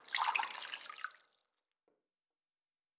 pgs/Assets/Audio/Fantasy Interface Sounds/Food Drink 03.wav at master
Food Drink 03.wav